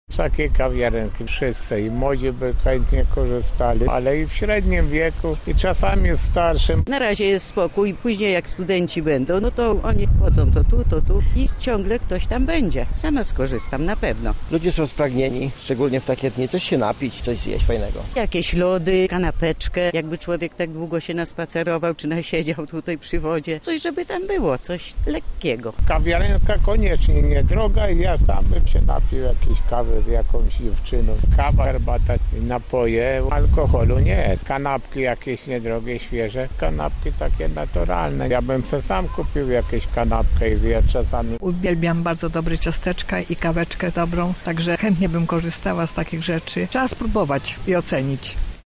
Nasza reporterka zapytała lublinian o zdanie na temat kawiarenki: